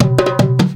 PERC 04.AI.wav